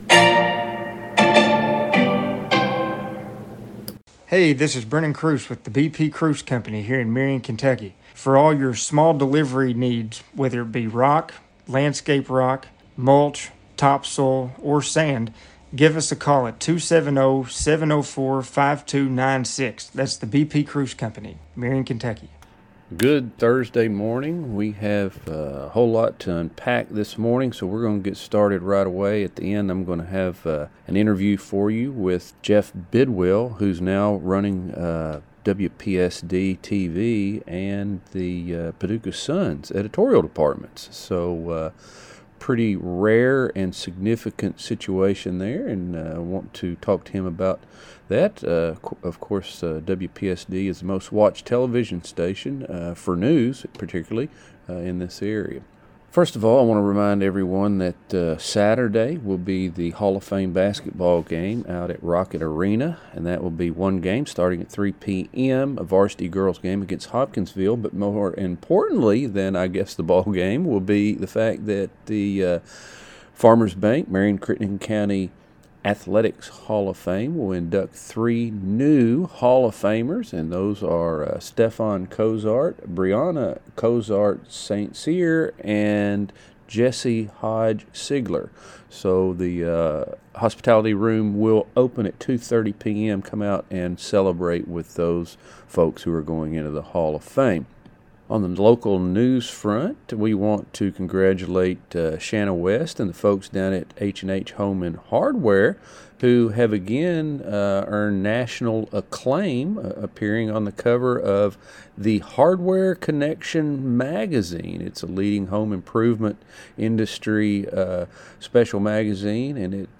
YOUR THURSDAY | LOCAL NEWScast